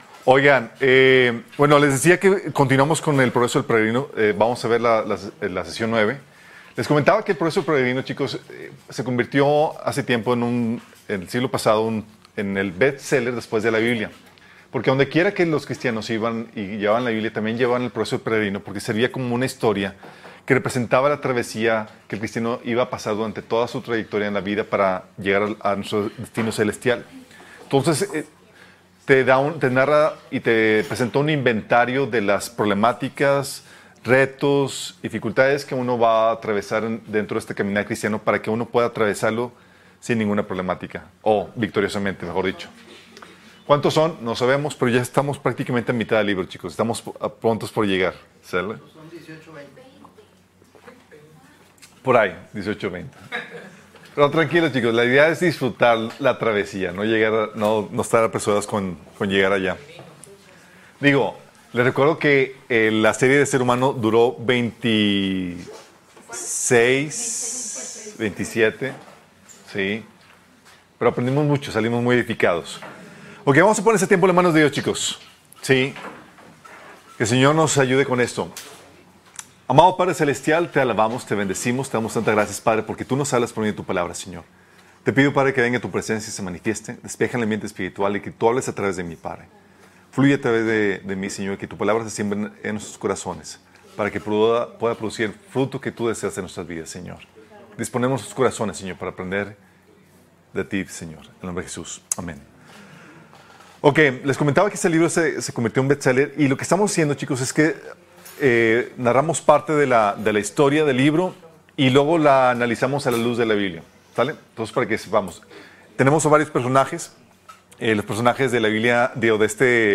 Encuentra aquí las predicaciones semanales que complementan el discipulado.